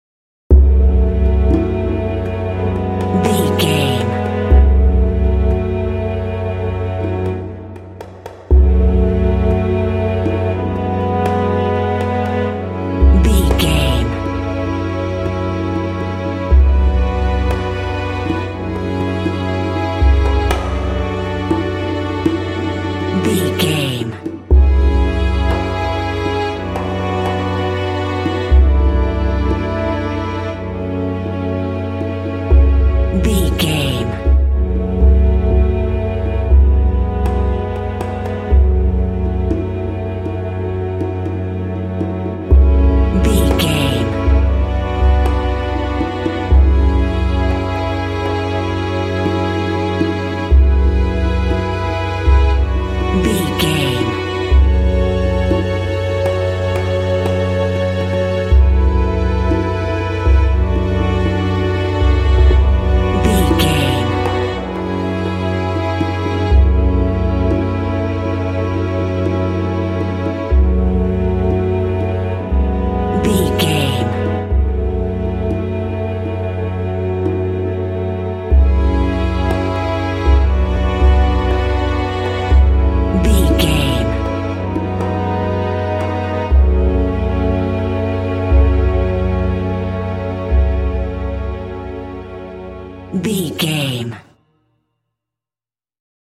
Aeolian/Minor
F#
dreamy
dramatic
strings
percussion